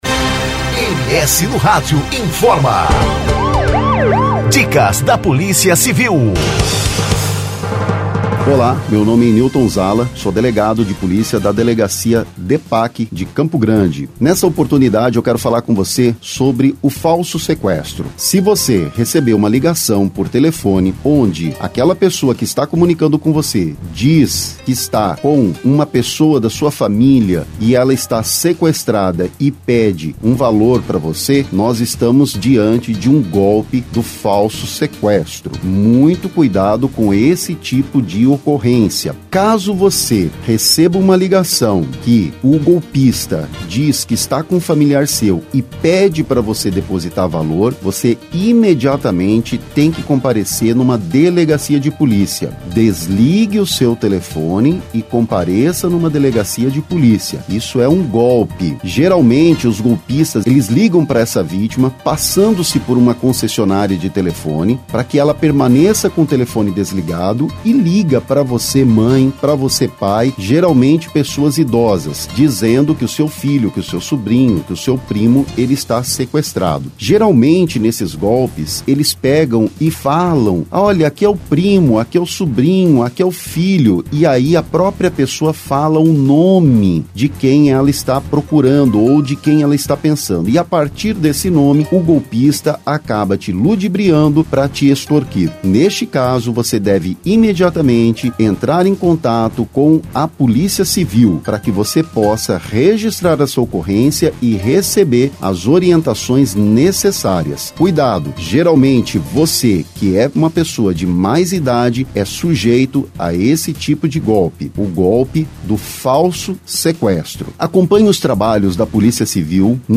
O delegado ainda alerta que para este tipo de golpe normalmente são escolhidas pessoas de mais idade.